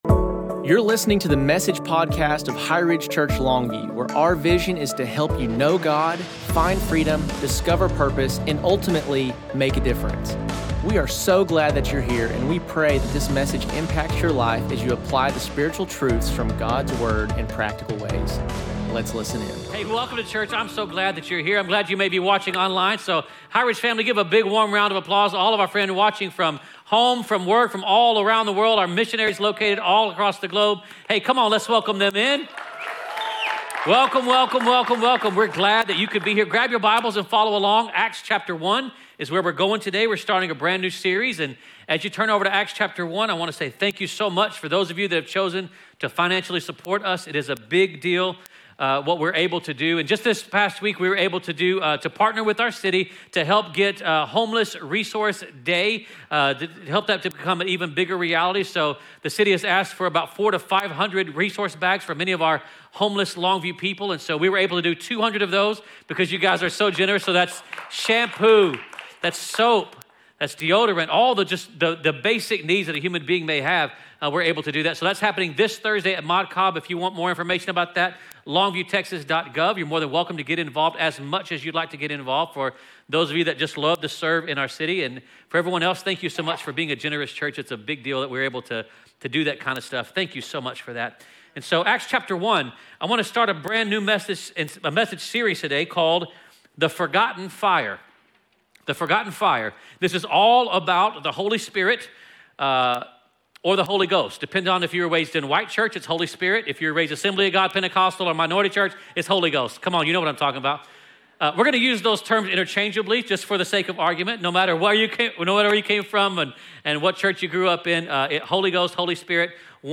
Message: The Forgotten Fire (Who Is The Holy Spirit)